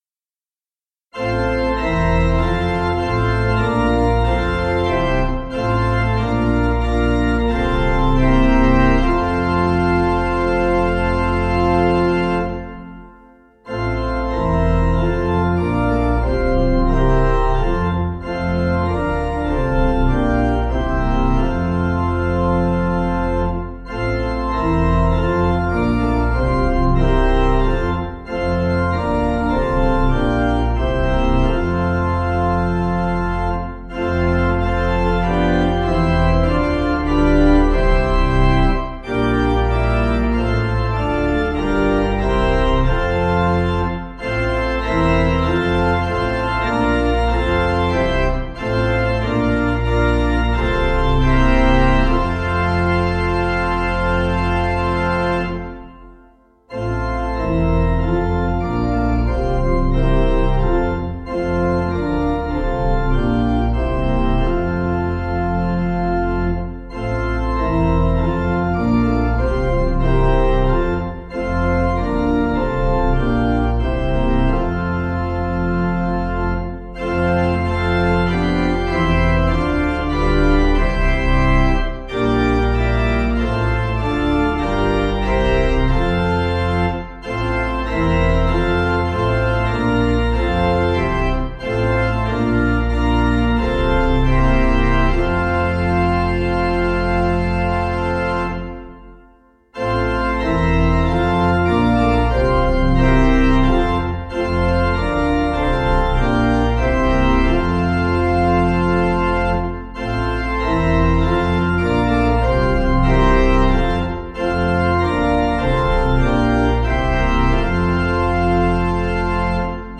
Key: F Major